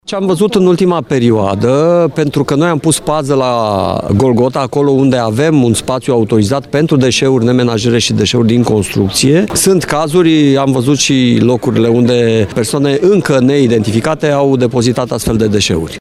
Potrivit Gazetei de Dimineață, saci cu moloz, gunoi și plastic împânzesc zona, iar primarul Tiberiu Iacob-Ridzi spune că nu se știe cine le-a depozitat.